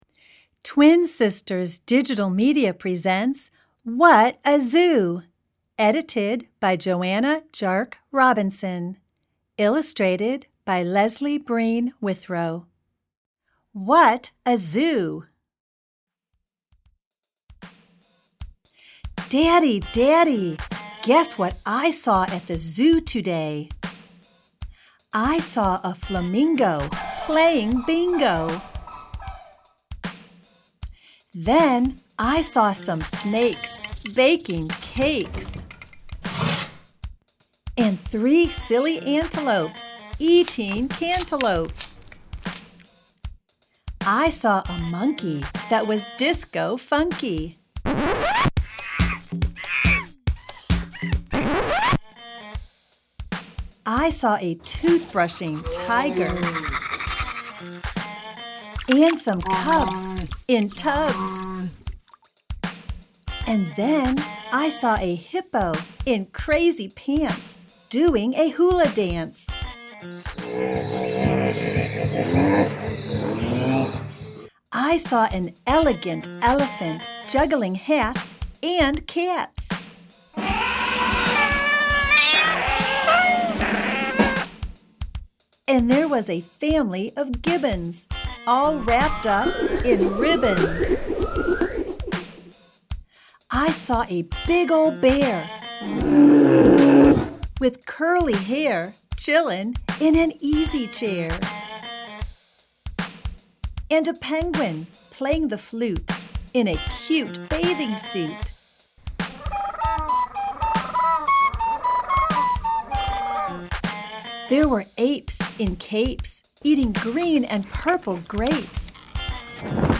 Reading What A Zoo